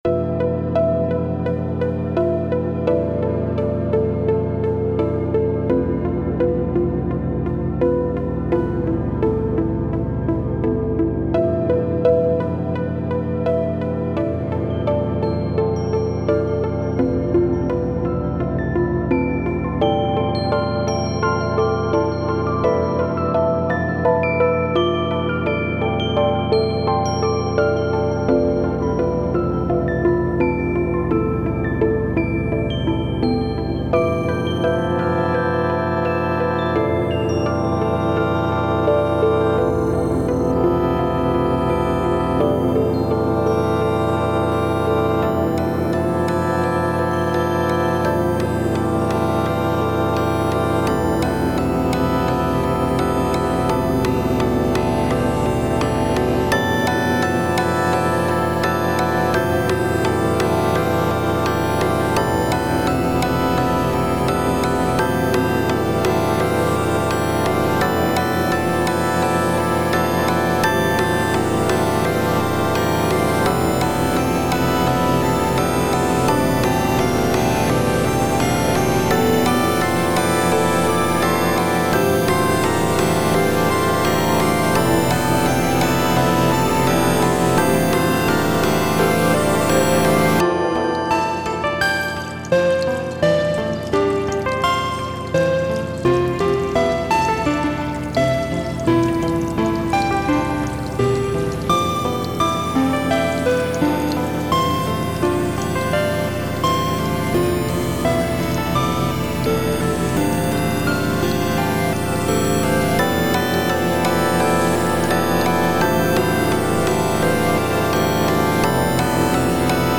タグ: アンビエント 不気味/奇妙 寂しい/悲しい 幻想的 虚無/退廃 コメント: 人工楽園をイメージしたBGM。